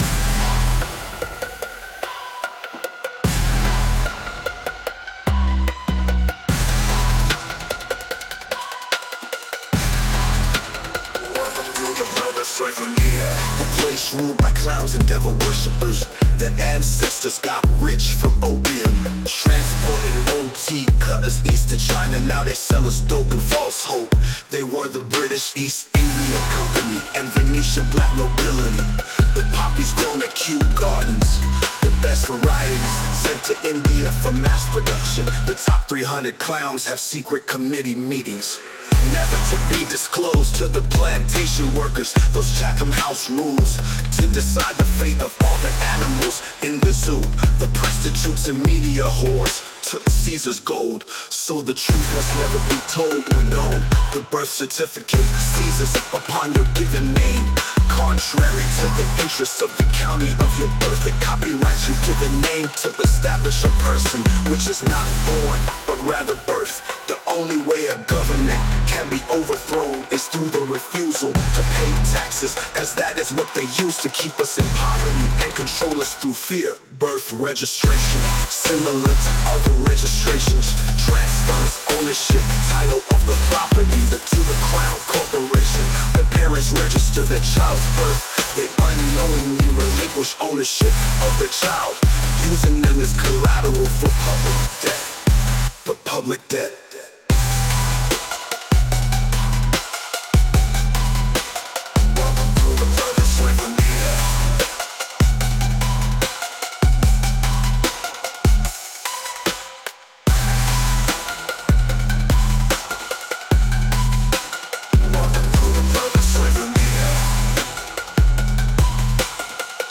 rap version]